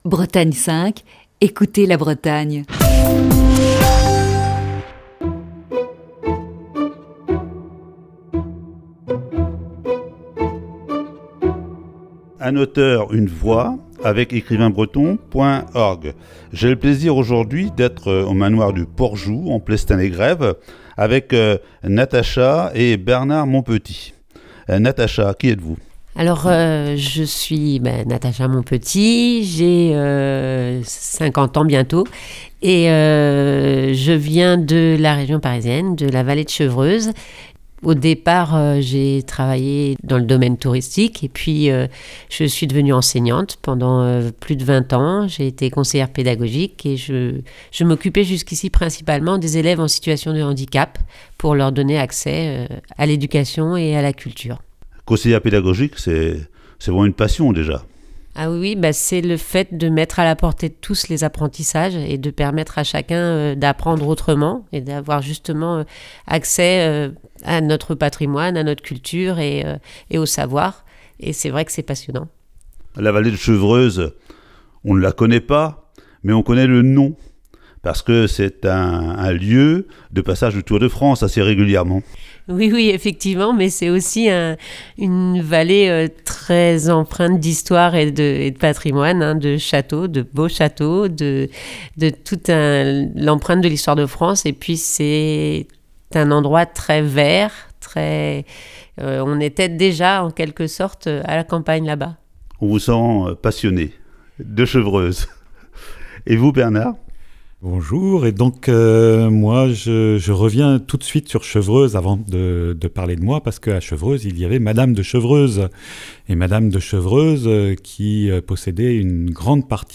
Voici ce matin, la première partie de cette série d'entretiens.